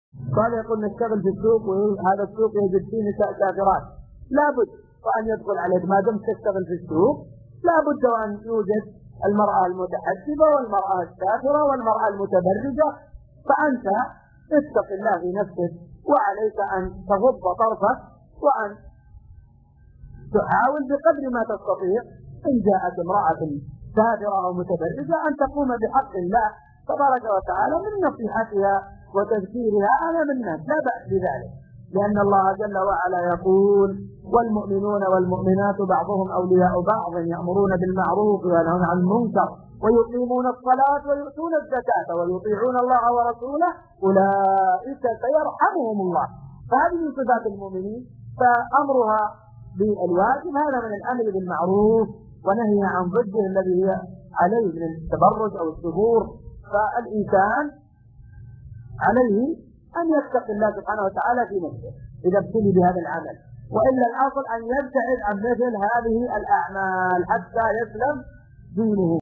السؤال من دروس شرح كتاب الصيام من زاد المستقنع .